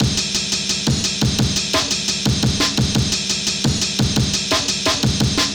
syndicate_amen_02.wav